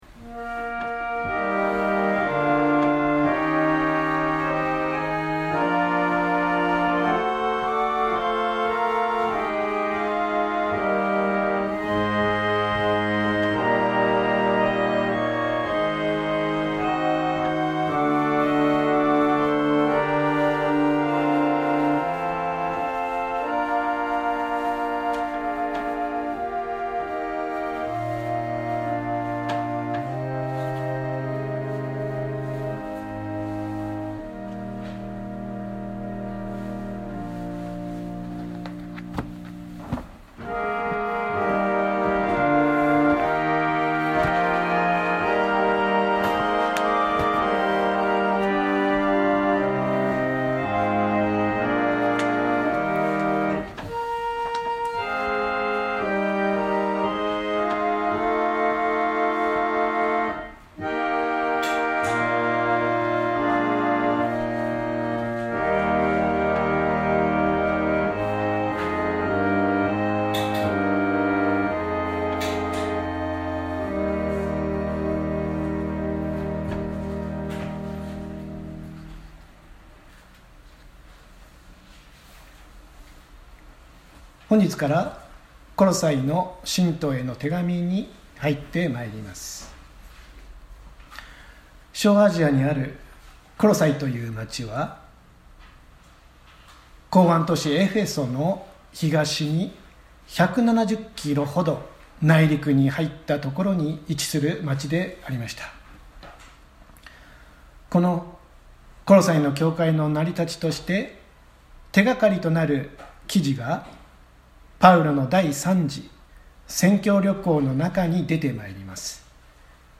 千間台教会。説教アーカイブ。